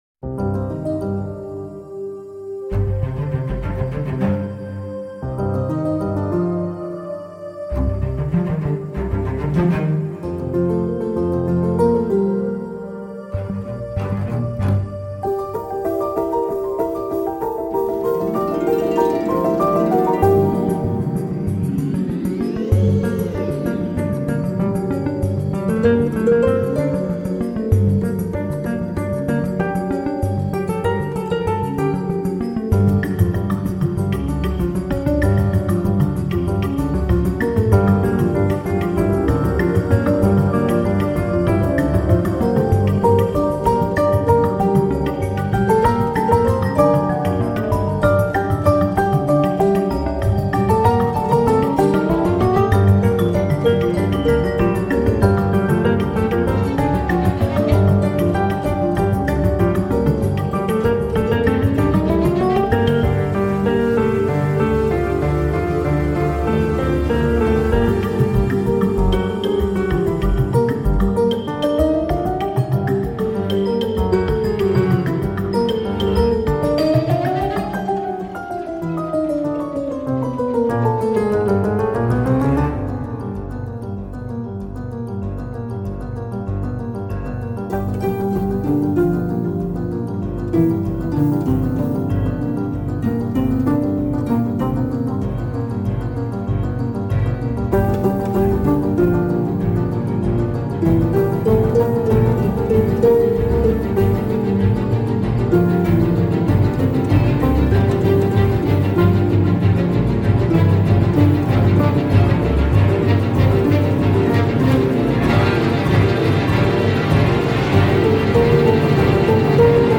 Un générique alerte et entrainant
Magie, sensualité et mystère